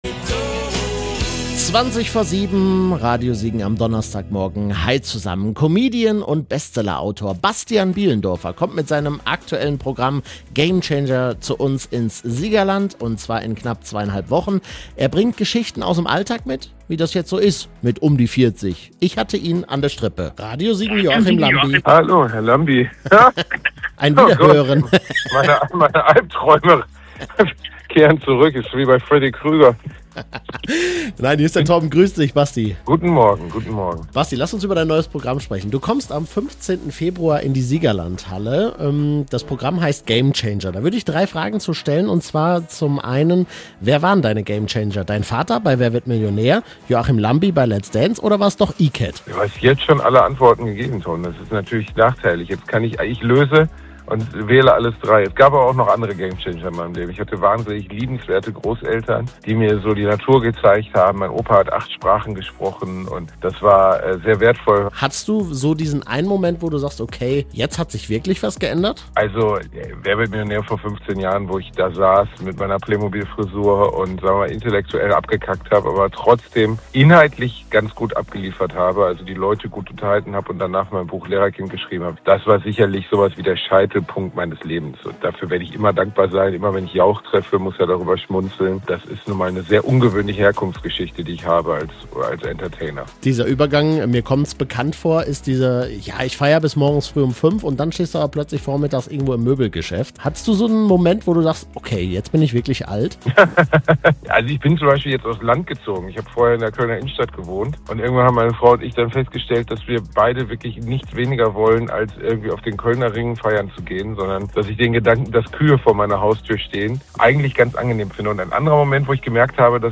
Bastian Bielendorfer im Radio Siegen Interview
bastian-bielendorfer-im-interview-teil-1.mp3